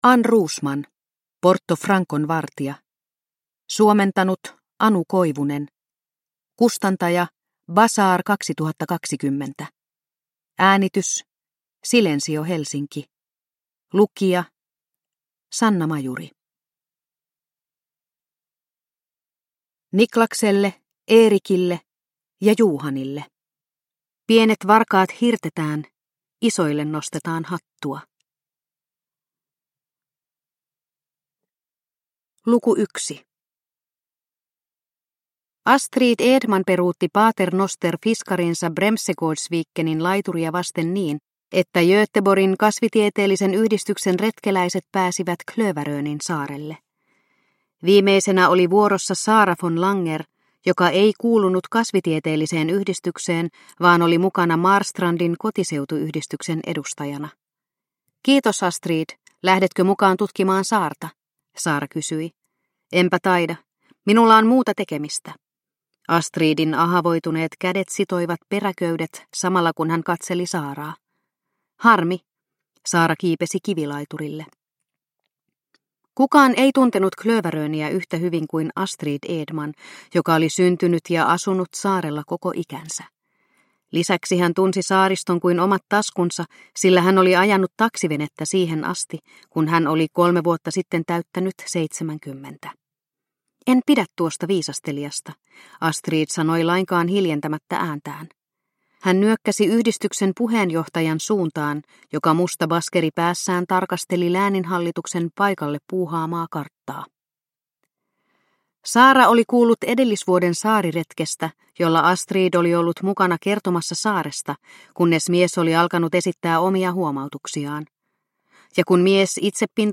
Porto Francon vartija – Ljudbok – Laddas ner